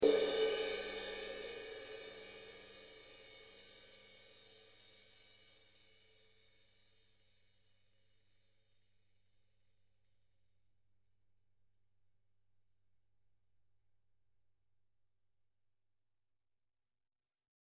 Zildjian 19" Armand Zildjian Beautiful Baby Ride Cymbal
19 riveted ride cymbal. Captures the A vintage sound. Great stick definition, over tones and plenty of sizzle.
It has a bright and musical, vintage A sound with focused stick definition, balanced wash and plenty of sizzle....